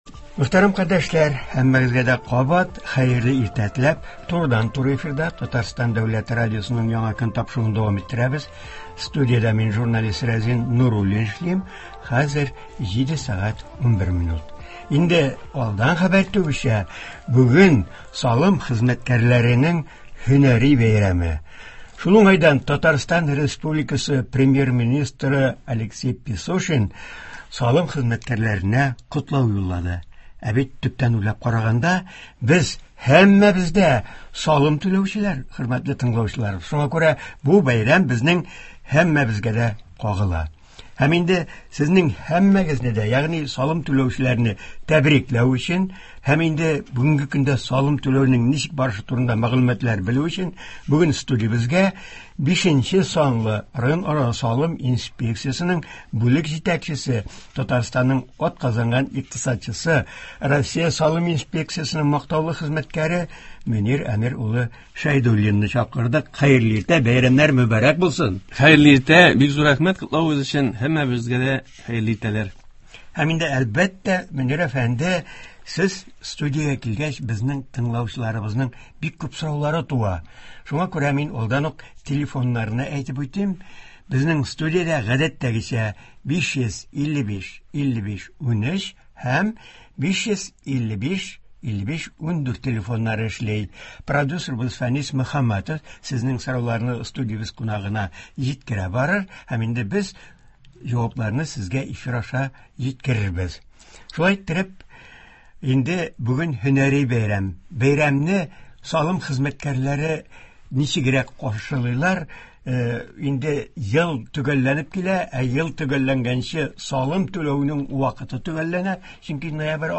турыдан-туры эфирда